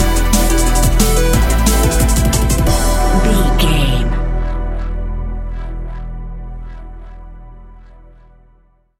Fast paced
Aeolian/Minor
aggressive
dark
energetic
intense
futuristic
synthesiser
drum machine
electronic
sub bass
synth leads